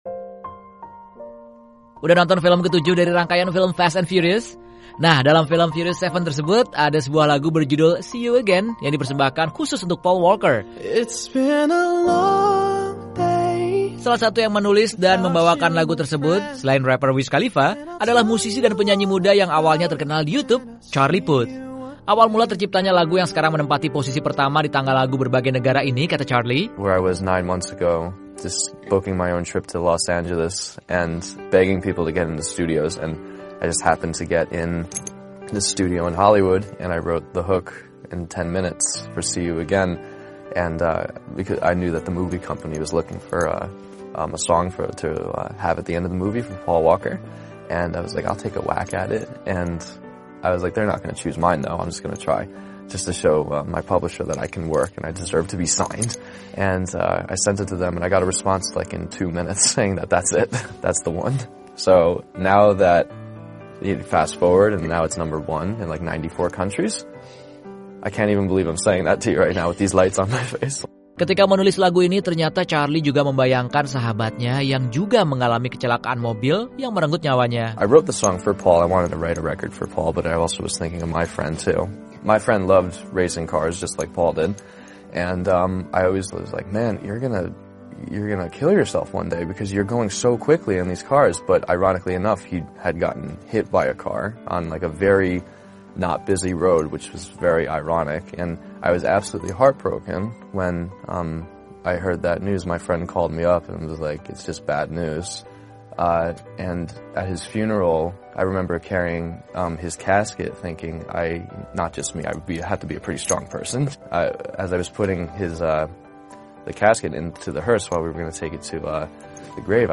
Kali ini ada obrolan bersama penyanyi dan penulis lagu, Charlie Puth, yang menulis dan membawakan lagu berjudul 'See You Again' bersama rapper, Wiz Khalifa, untuk mengenang Paul Walker di film Furious 7. Bagaimana cerita di balik pembuatan lagu tersebut?